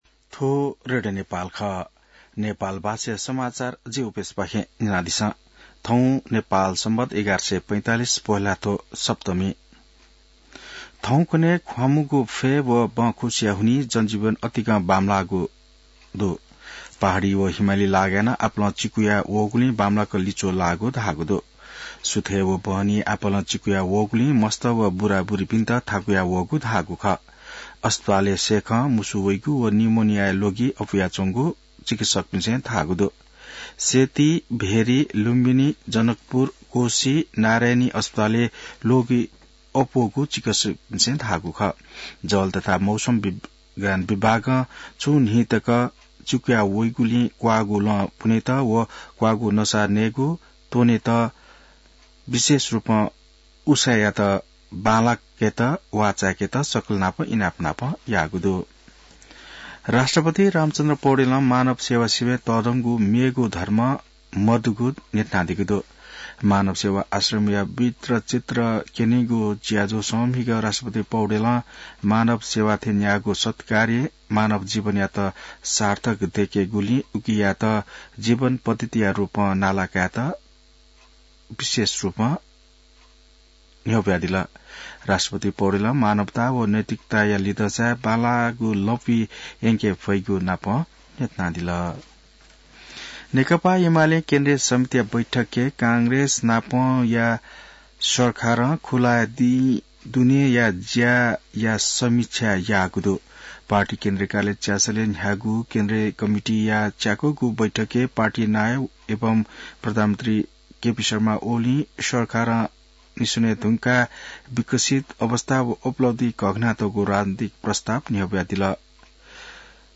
नेपाल भाषामा समाचार : २३ पुष , २०८१